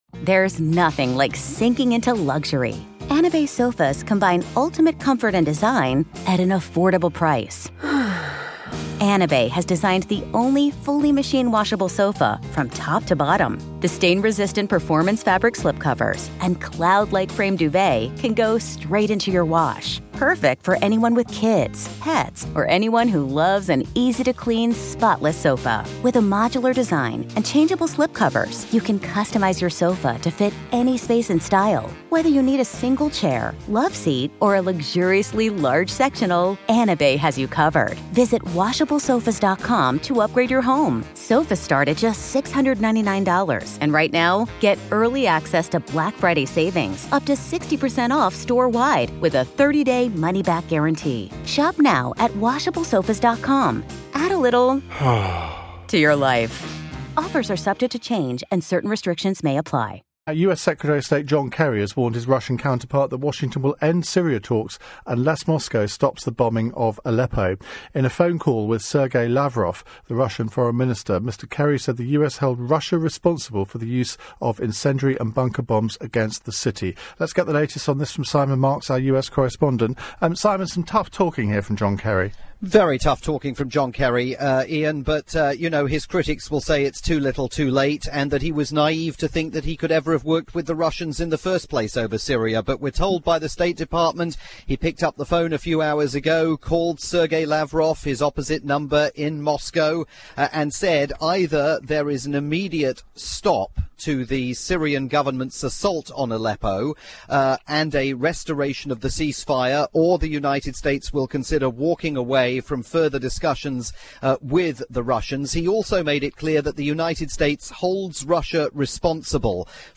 report aired on "Iain Dale at Drive" on the UK's LBC.